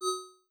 GenericNotification8.wav